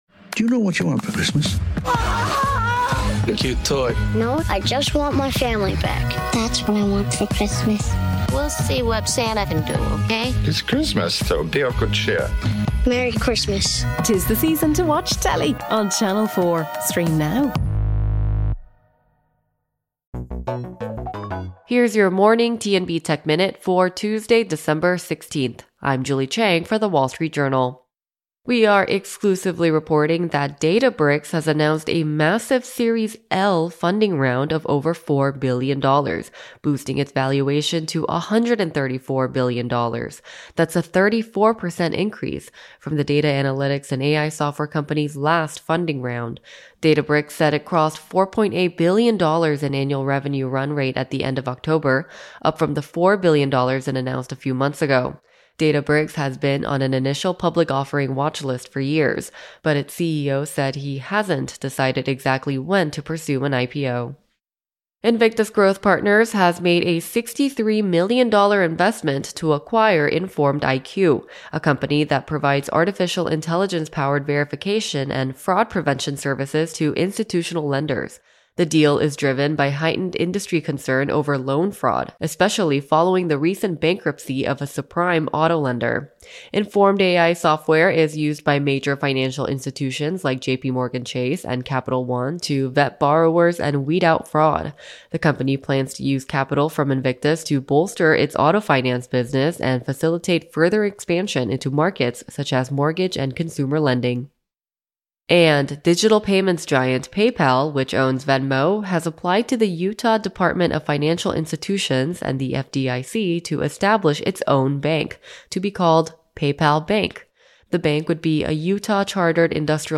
This week’s show captures the raw energy of a live set
We’re spinning an hour of 1960's dancefloor fire—garage rock, Northern Soul, and hard-hitting R&B—played loud and loose for a great cause.